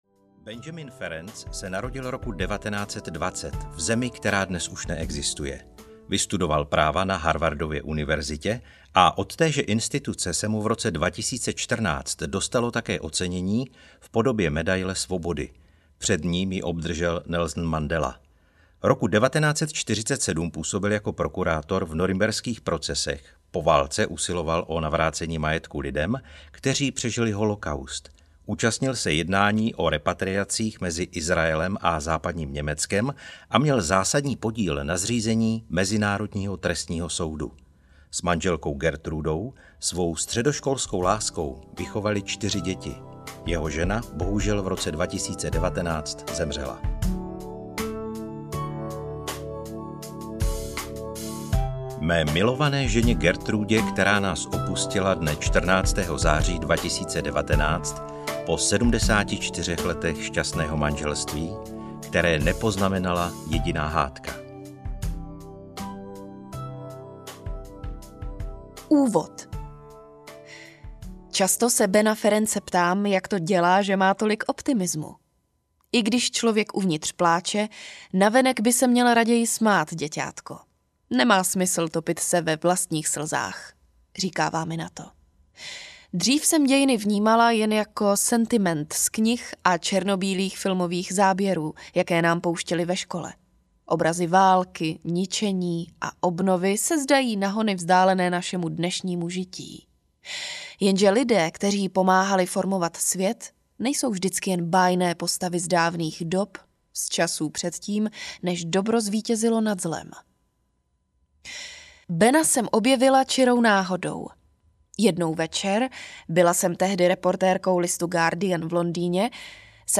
Nikdy se nevzdávej audiokniha
Ukázka z knihy